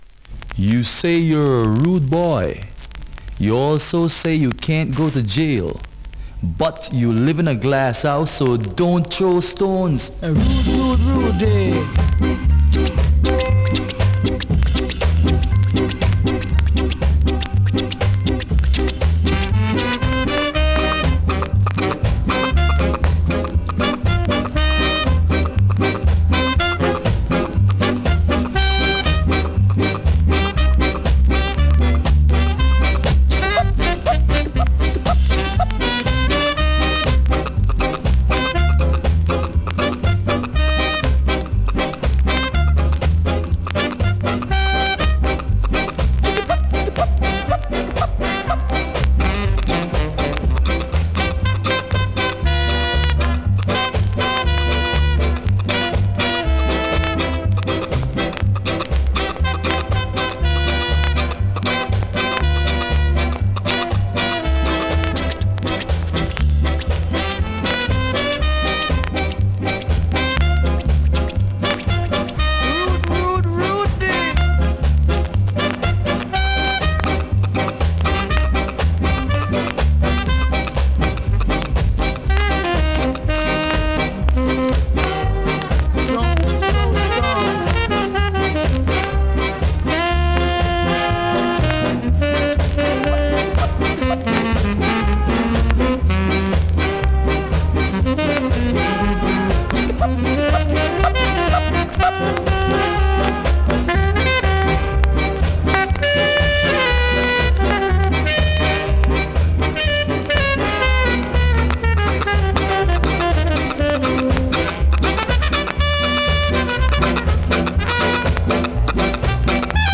はじめ少しノイズ、プレイはOK)   コメントレアSKA!!
スリキズ、ノイズそこそこありますが